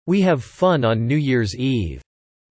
Reading – New Year’s Eve